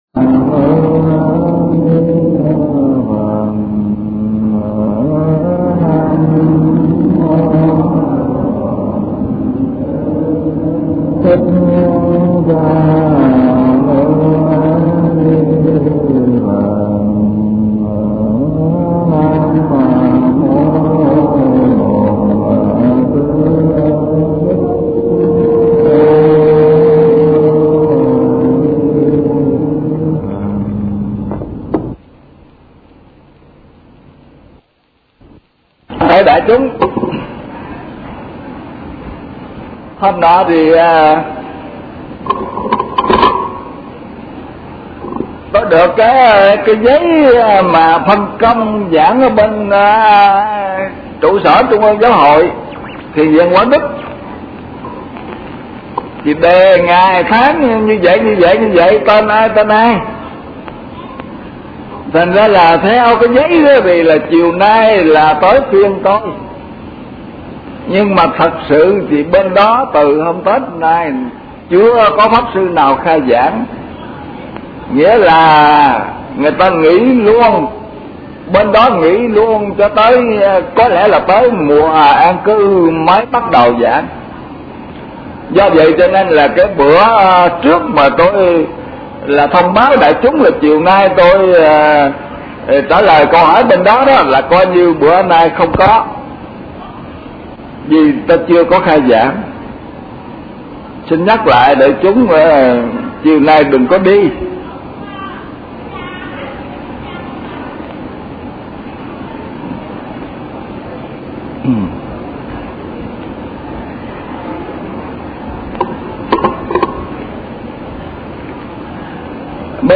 Kinh Giảng Kinh Pháp Hoa